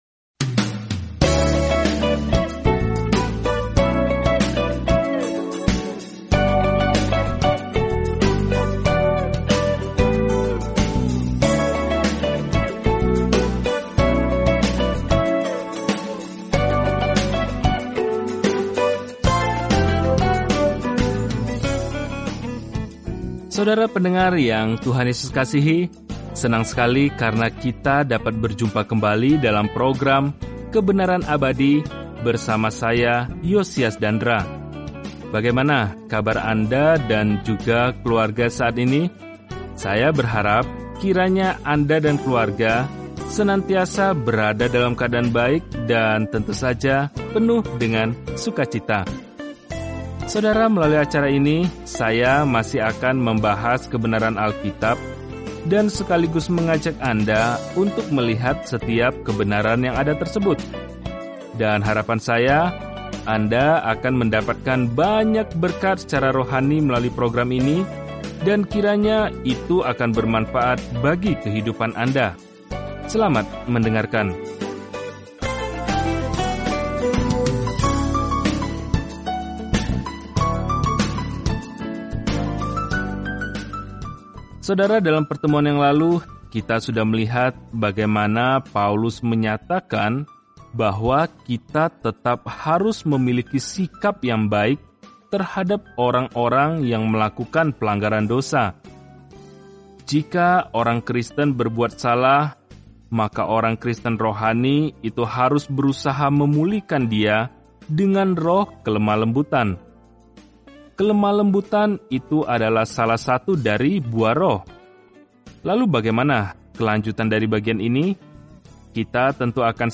Firman Tuhan, Alkitab Galatia 6:6-11 Hari 18 Mulai Rencana ini Hari 20 Tentang Rencana ini “Hanya melalui iman” kita diselamatkan, bukan melalui apa pun yang kita lakukan untuk layak menerima anugerah keselamatan – itulah pesan yang jelas dan langsung dari surat kepada jemaat Galatia. Jelajahi Galatia setiap hari sambil mendengarkan pelajaran audio dan membaca ayat-ayat tertentu dari firman Tuhan.